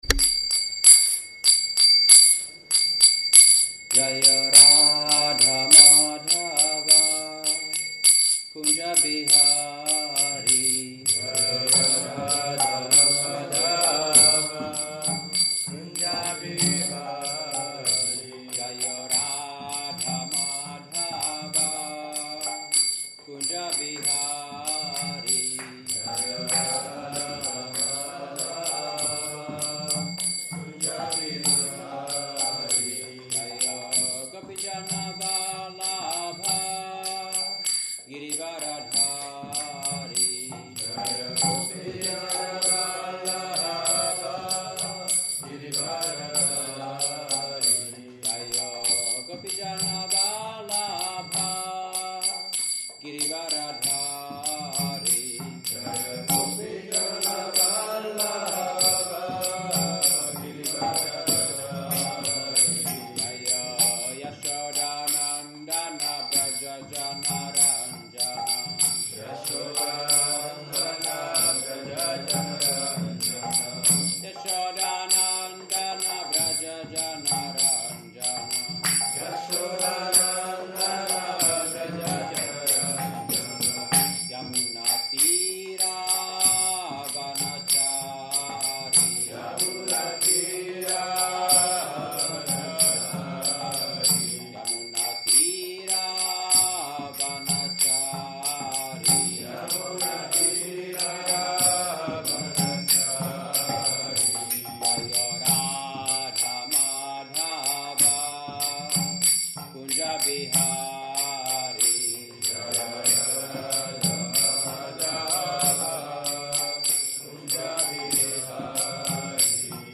Bhajana, kirtana